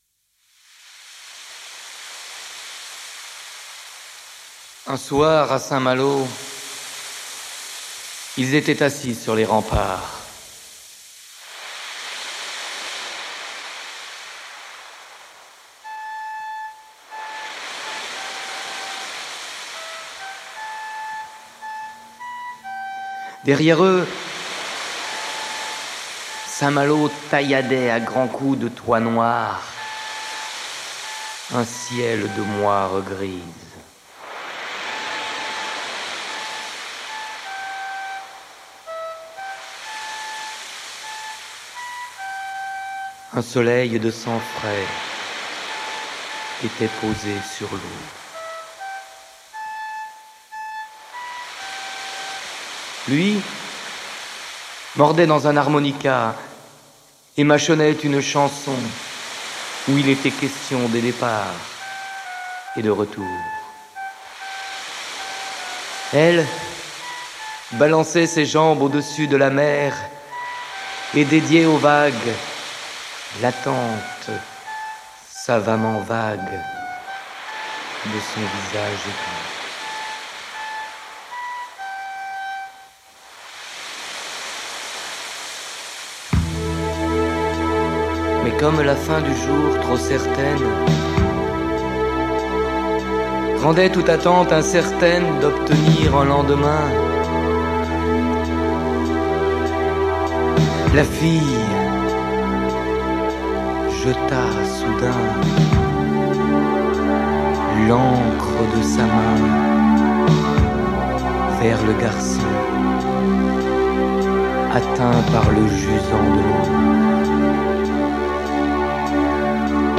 Texte récité